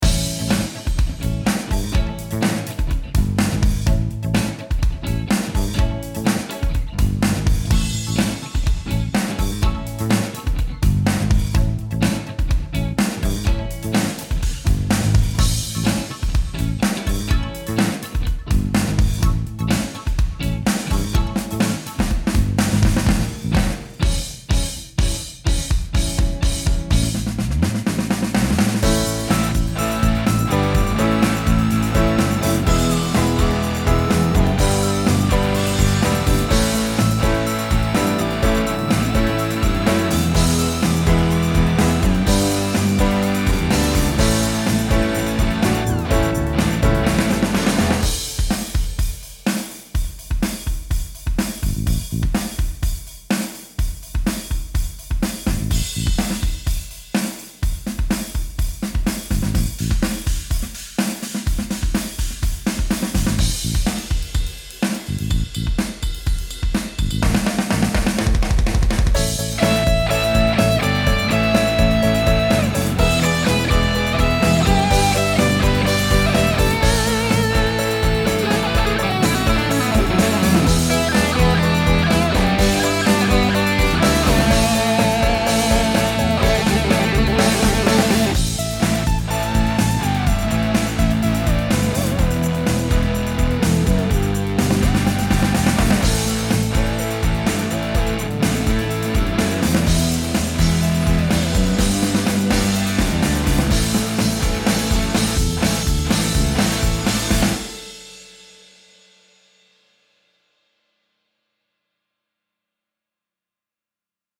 hot-pads-for-love-instrumental1.mp3